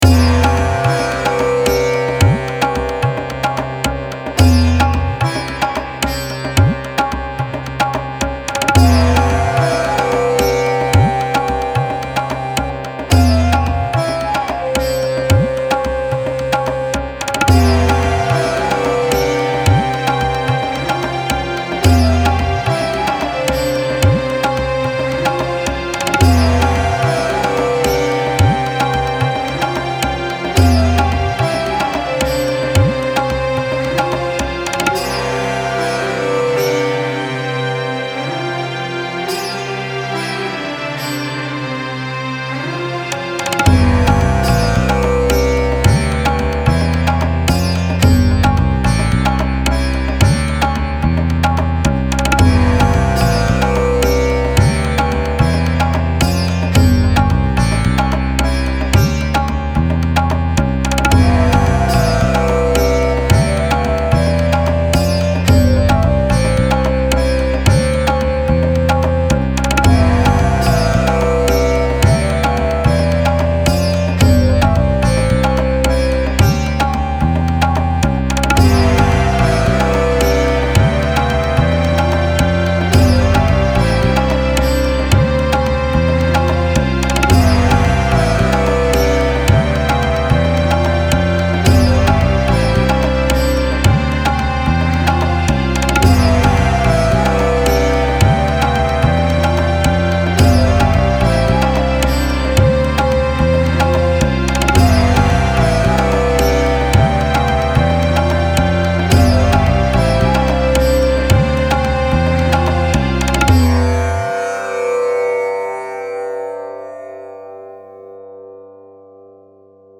Style Style World
Mood Mood Calming, Relaxed
Featured Featured Flute, Percussion, Strings
BPM BPM 110
Relaxing background music for anything really.